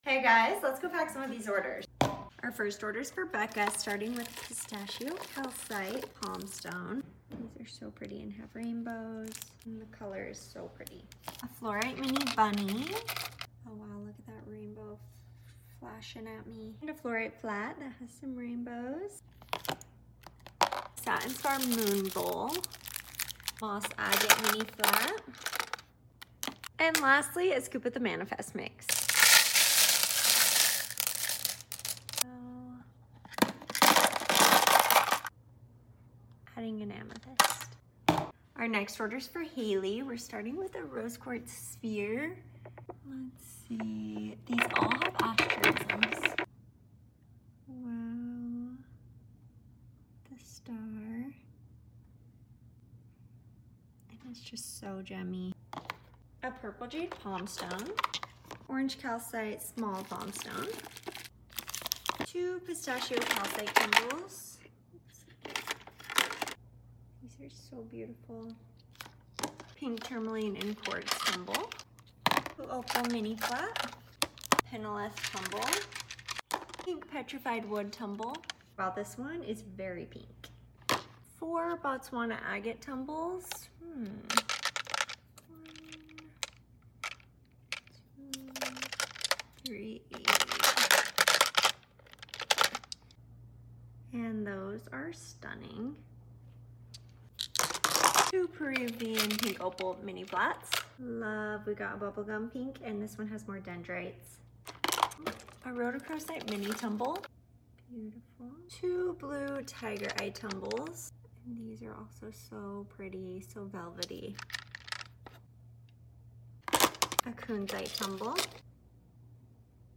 all asmr order packing going up this week